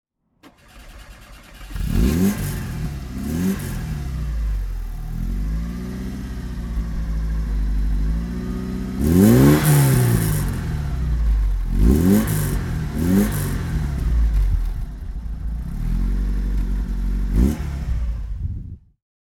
Alfa Romeo Alfasud ti 1.3 (1979) - Starten und Leerlauf